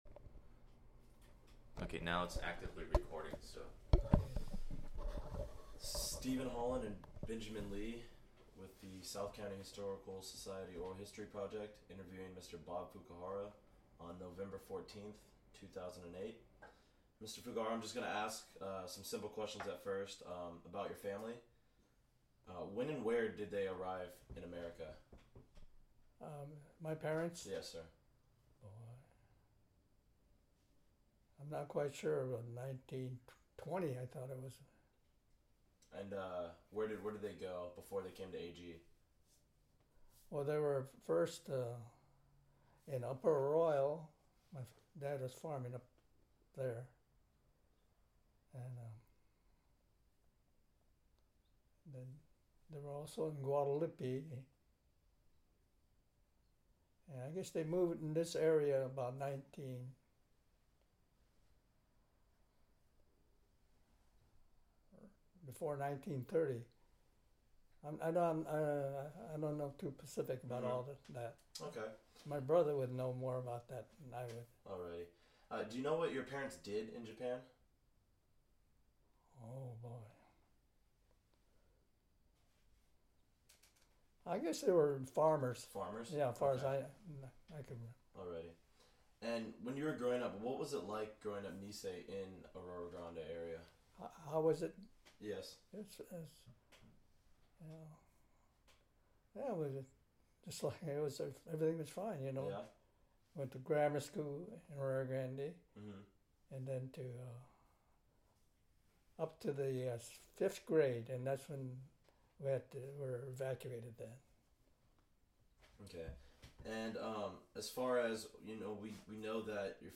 Ethnic Studies Oral History Collection